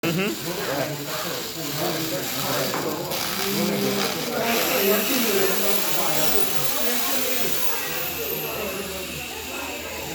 Merlin saw grinder.mp4